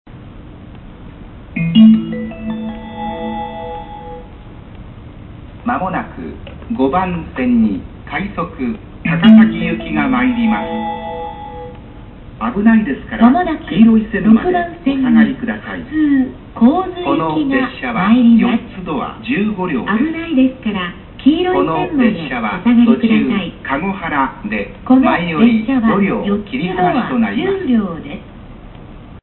接近放送快速高崎行き15両
湘南新宿ラインの快速高崎行きの接近放送です。
切り離し放送が横須賀線と同じです。